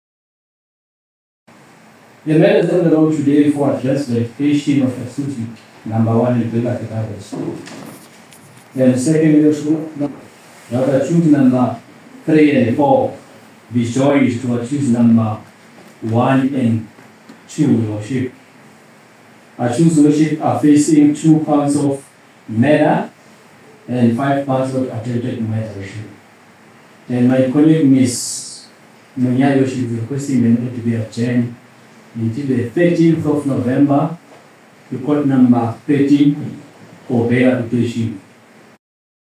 State prosecutor